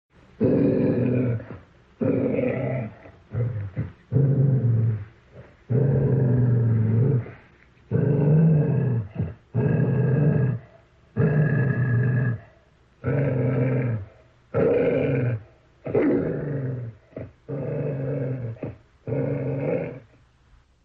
Dog Growl Voice-sound-HIingtone
dog-growl-voice.mp3